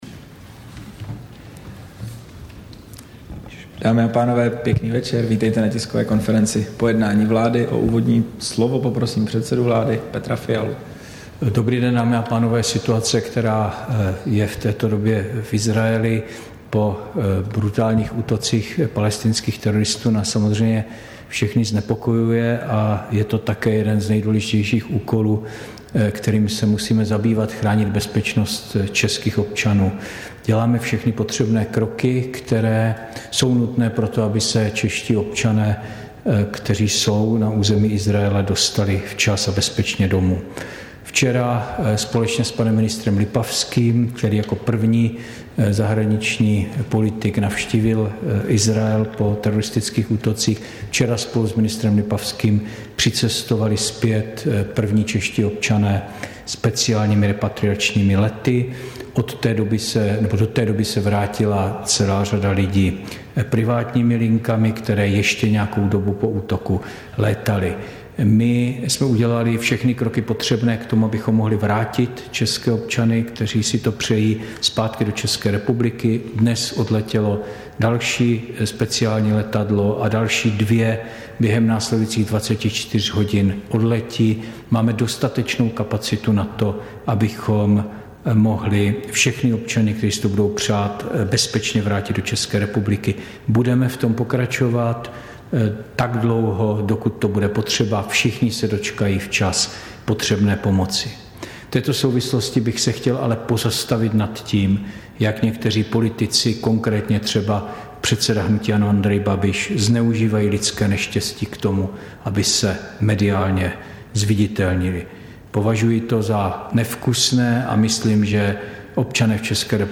Tisková konference po jednání vlády, 11. října 2023